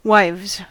Ääntäminen
Ääntäminen US : IPA : [waɪvz] Haettu sana löytyi näillä lähdekielillä: englanti Käännös 1. vaimot Wives on sanan wife monikko.